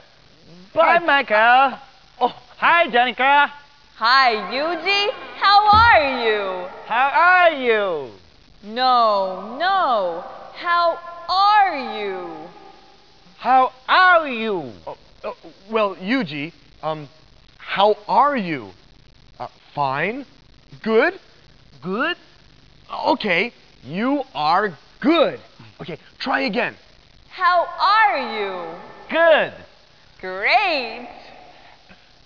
21 ON NET SKIT